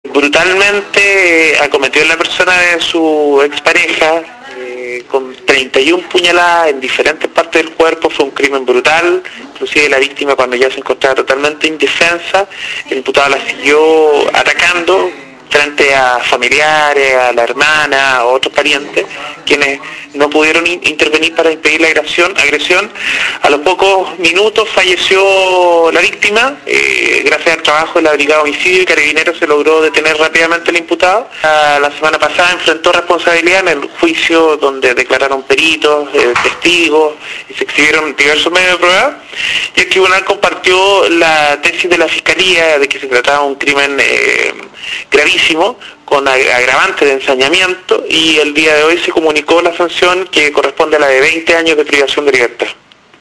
Fiscal Patricio Caroca se refiera a sentencia por delito de femicidio